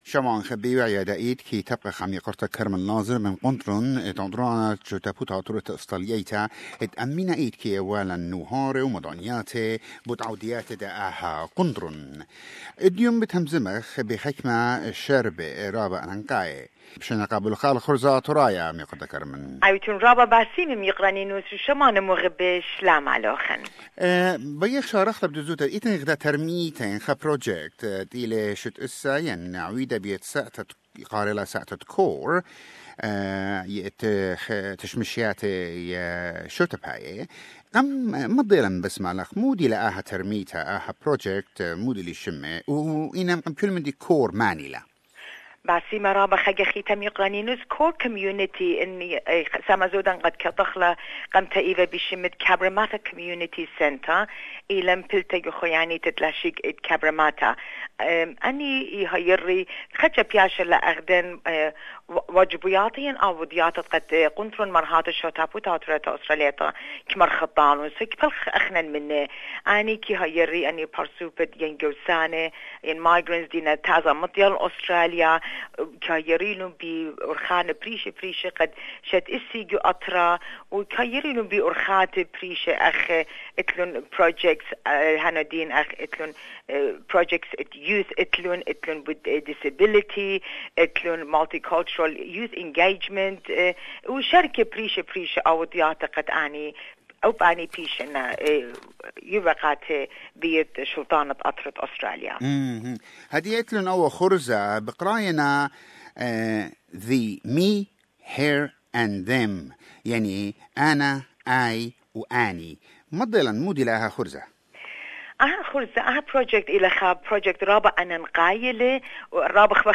These topics and more are discussed and explained in this interview